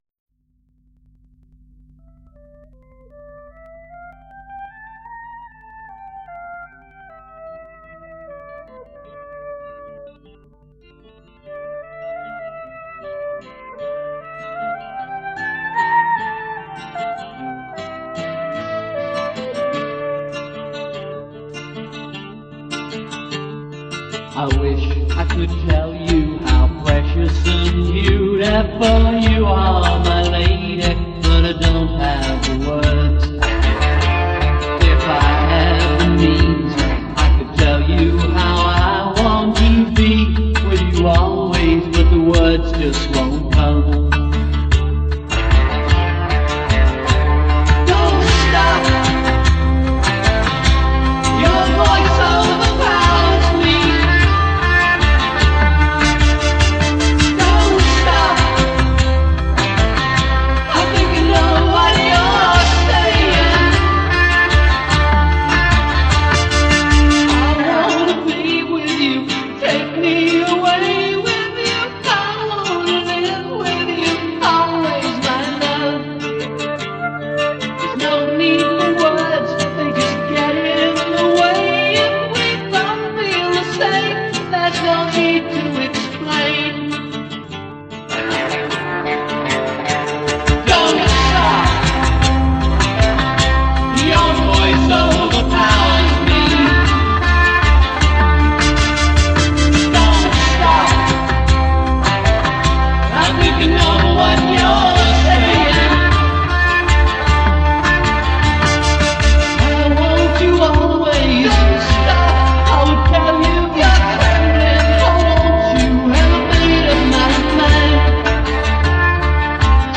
• Flute